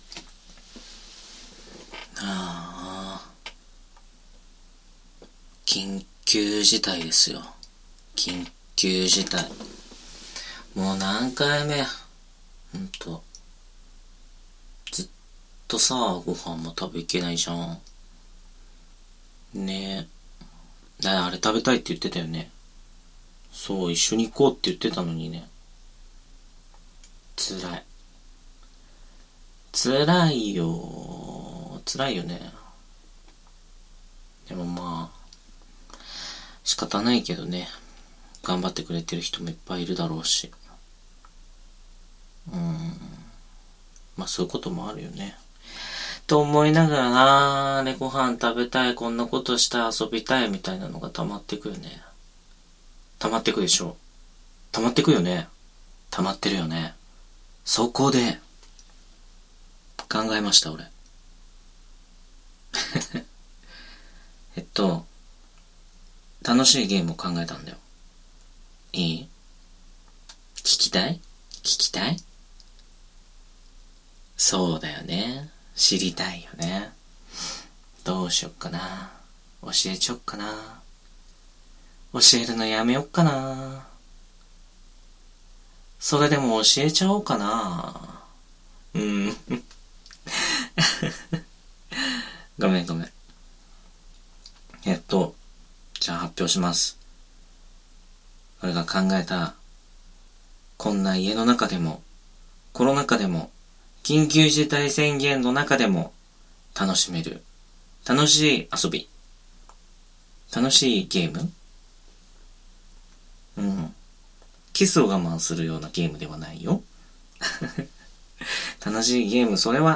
纯爱/甜蜜 治愈 男性受 女性向 环绕音 ASMR 舔耳 乙女向